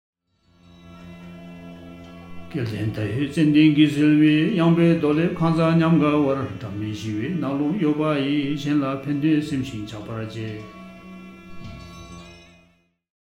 Karma_BCA_Chapter_8_verse_86_with_music.mp3